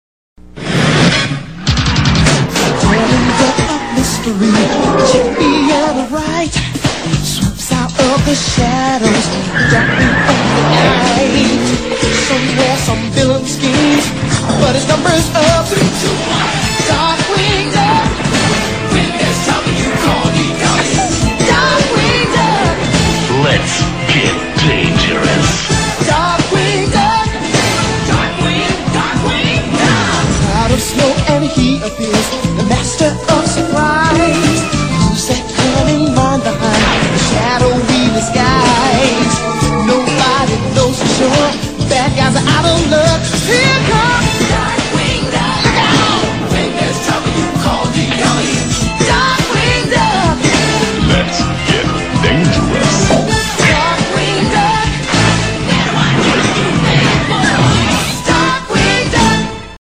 BPM104
Audio QualityCut From Video